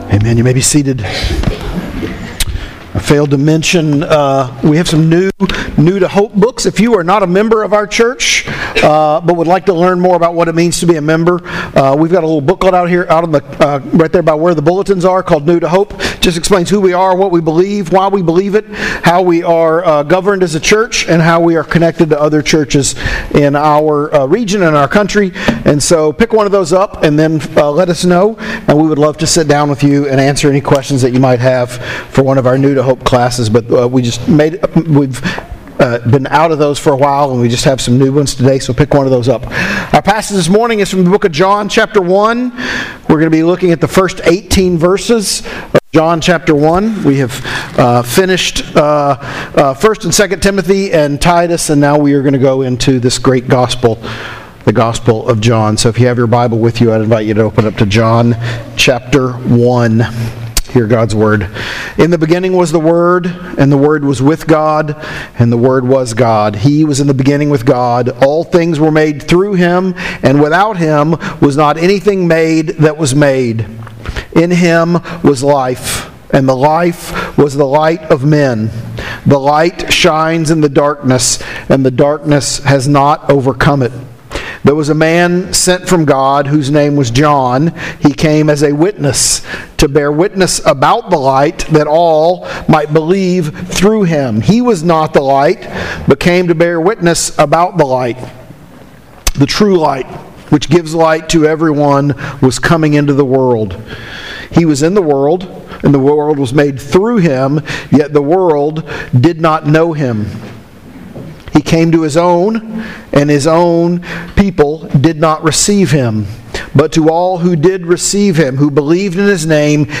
Sermons | Hope Church PCA